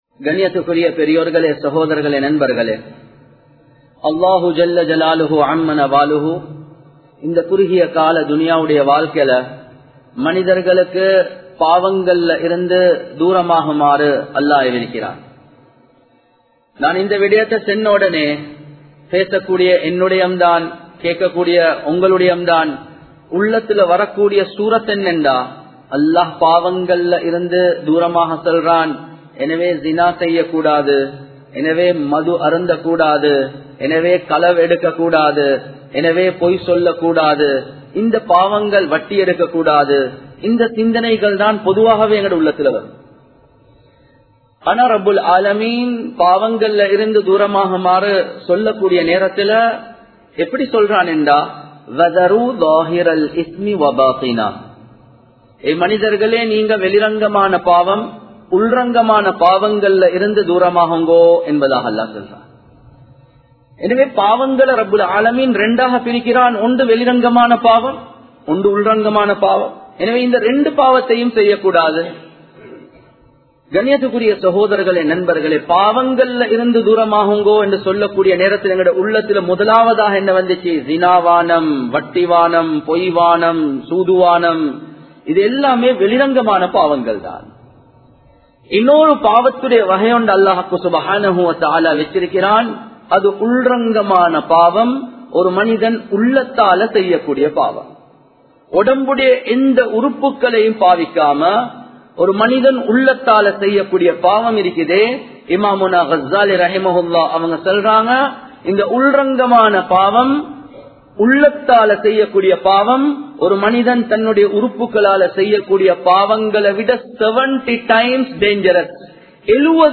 Perumaium Poraamaium (பெருமையும் பொறாமையும்) | Audio Bayans | All Ceylon Muslim Youth Community | Addalaichenai